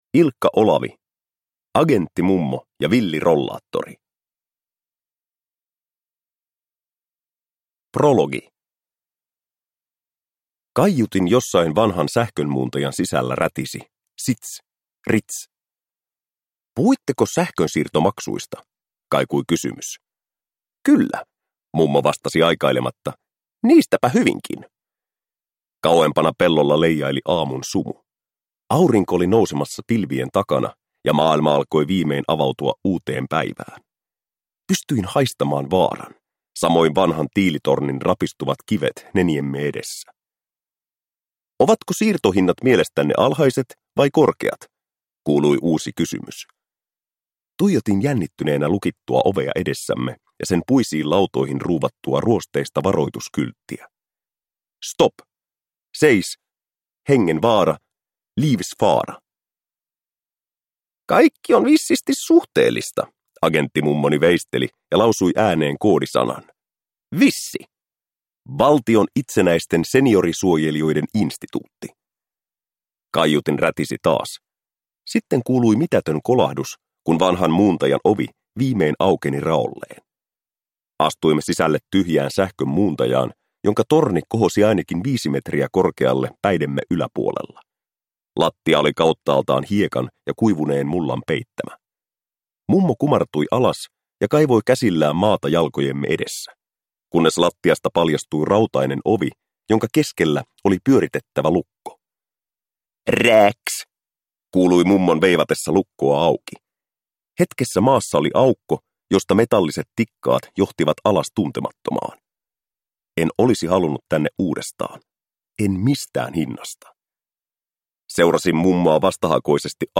Agenttimummo ja villi rollaattori – Ljudbok